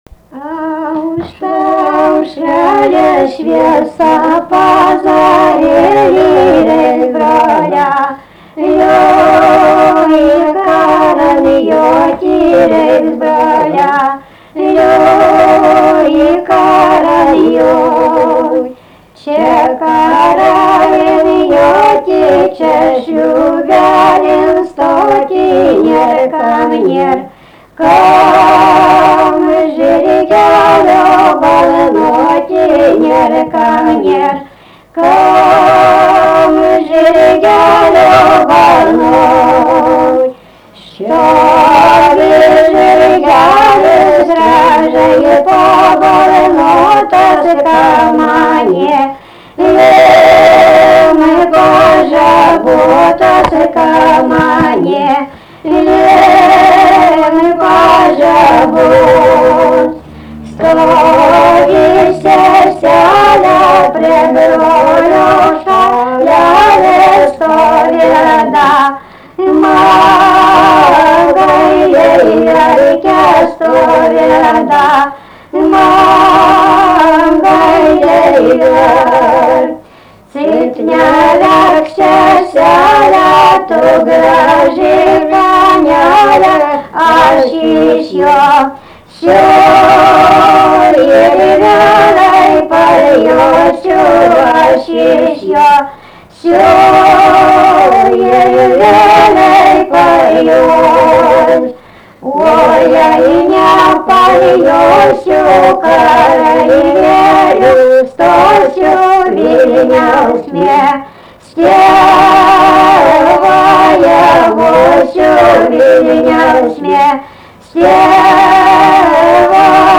Tipas daina Erdvinė aprėptis Tameliai
Atlikimo pubūdis vokalinis